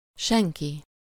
Ääntäminen
France: IPA: [yn pɛʁ.sɔn]